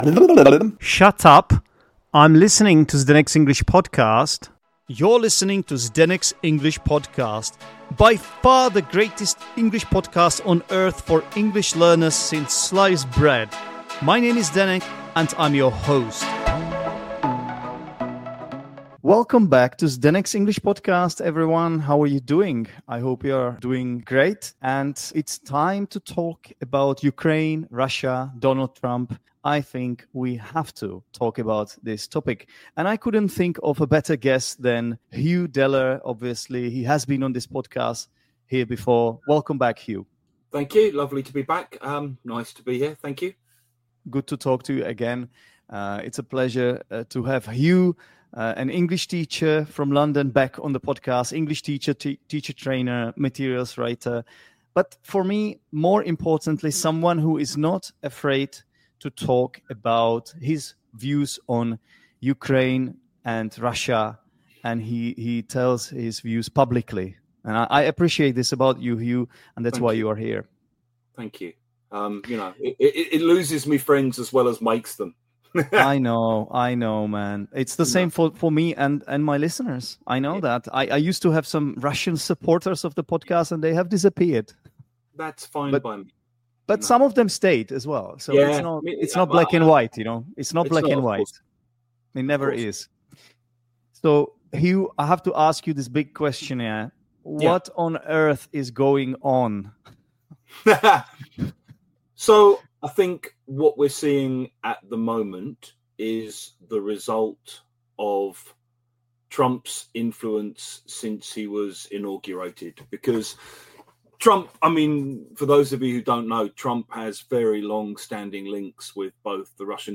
A thought-provoking discussion on war, politics, and the future.